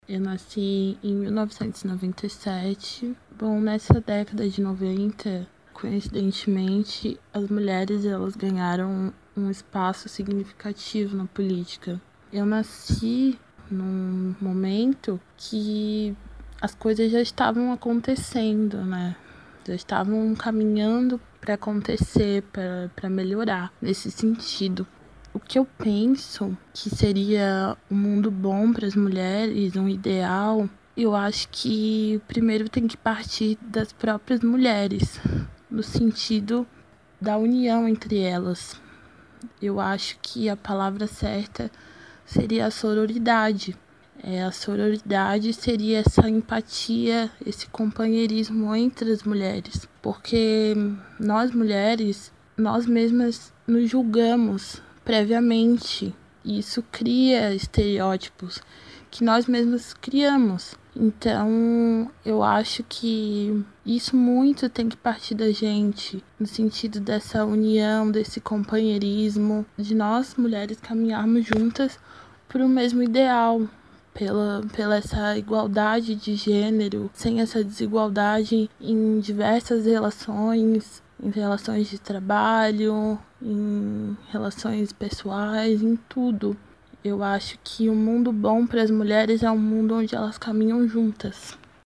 Ouvimos mulheres de várias idades, que viveram momentos diferentes dessa história, para saber o que ainda falta para o mundo se tornar um lugar bom para elas.